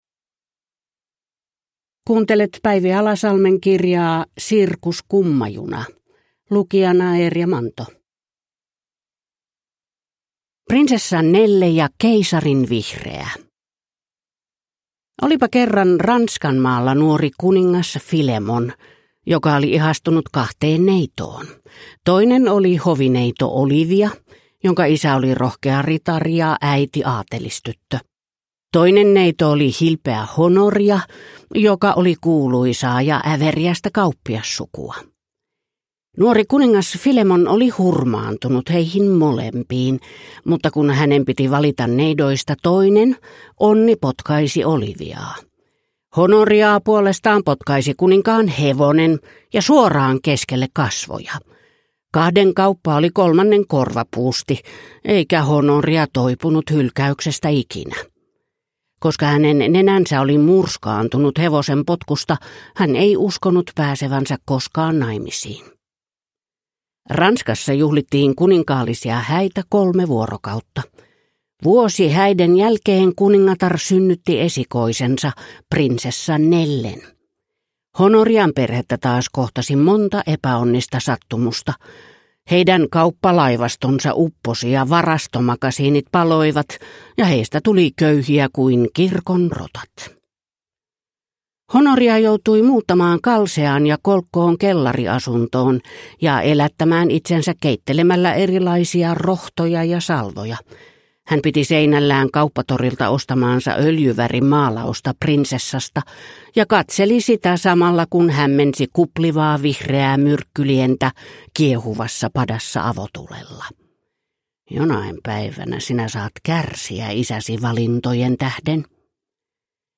Sirkus Kummajuna (ljudbok) av Päivi Alasalmi